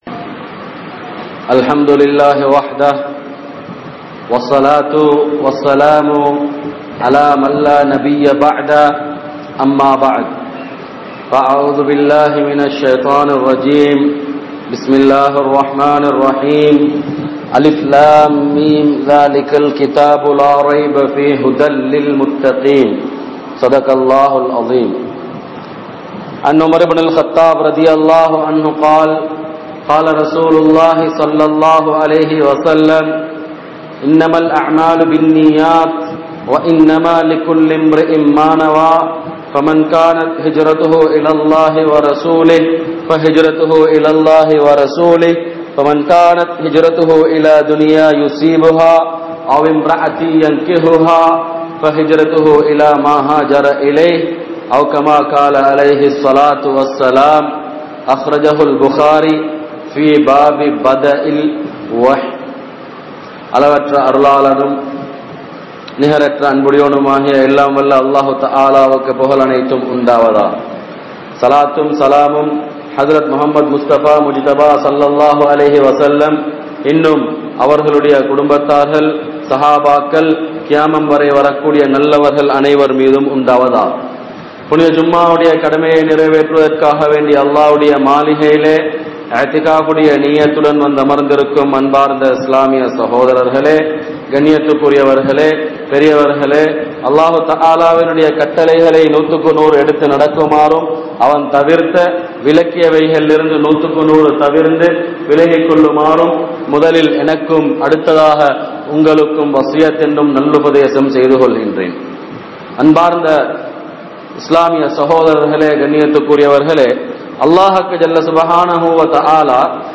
Manitharhalai Mathiungal (மனிதர்களை மதியுங்கள்) | Audio Bayans | All Ceylon Muslim Youth Community | Addalaichenai
Sugathadasa Indoor Stadium Jumua Masjidh